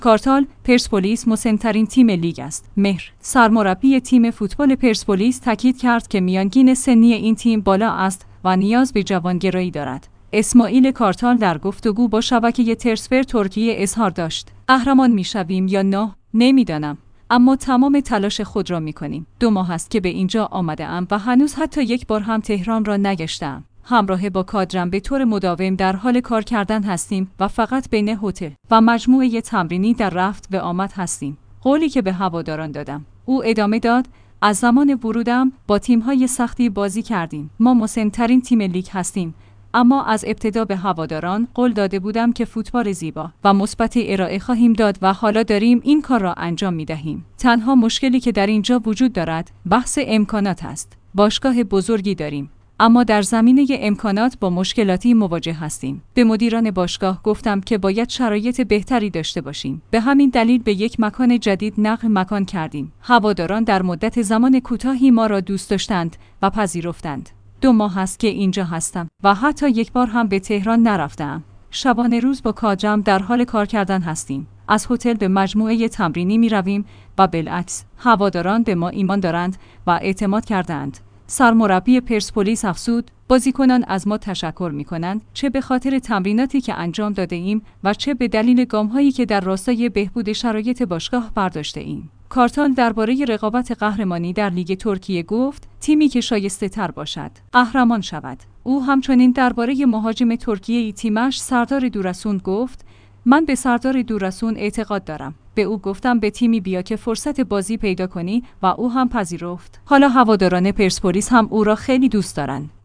مهر/ سرمربی تیم فوتبال پرسپولیس تاکید کرد که میانگین سنی این تیم بالا است و نیاز به جوان گرایی دارد. اسماعیل کارتال در گفتگو با شبکه TRTSPOR ترکیه اظهار داشت: قهرمان می‌شویم یا نه، نمی‌دانم، اما تمام تلاش خود را می‌کنیم.